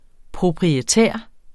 Udtale [ pʁobʁiˈtεˀɐ̯ ]